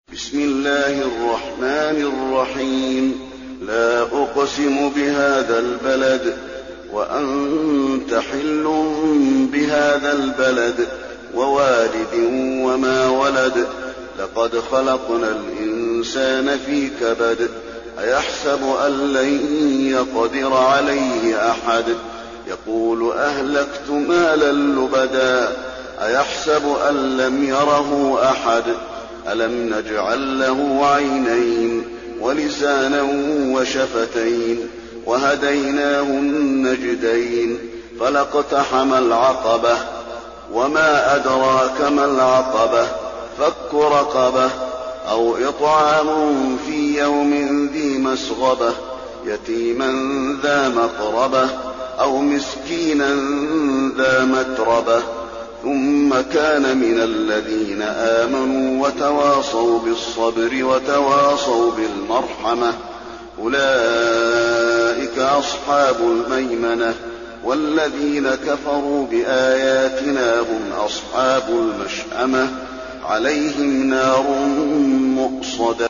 المكان: المسجد النبوي البلد